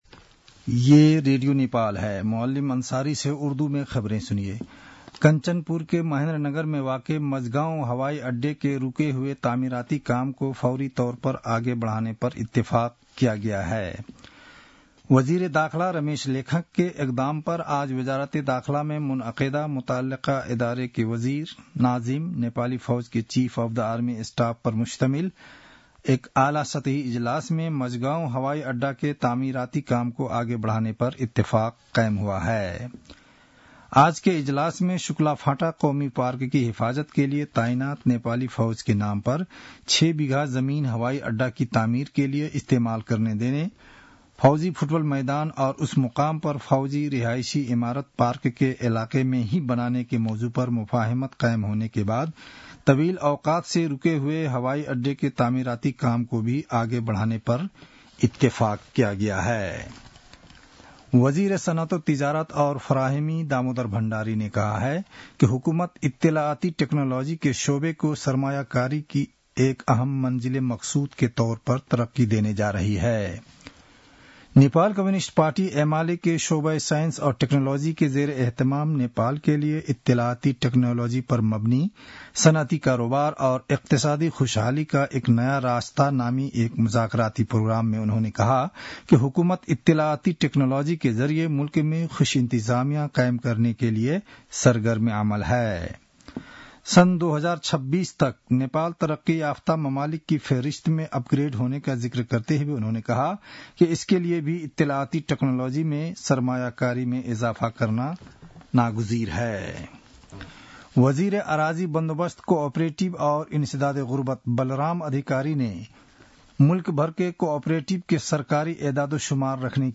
उर्दु भाषामा समाचार : ३ फागुन , २०८१